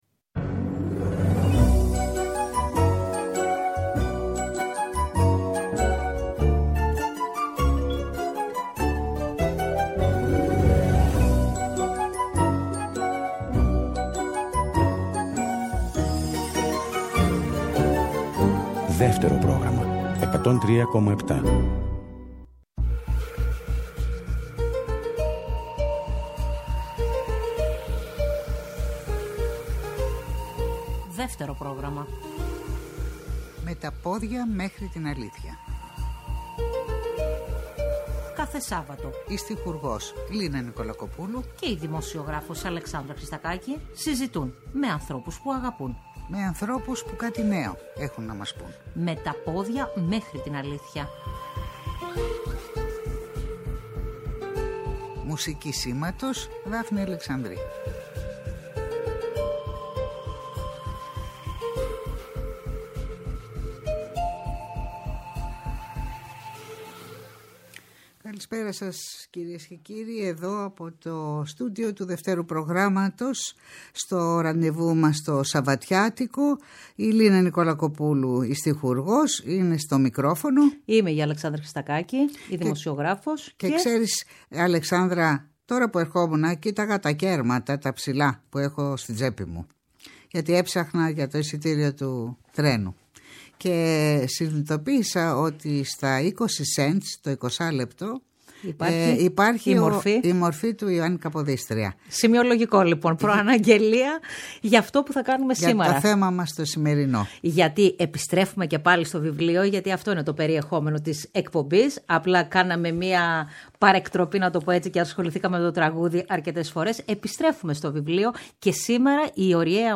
συζητούν με την ιστορικό Λένα Διβάνη για την προσωπικότητα του Α’ Κυβερνήτη της Ελλάδας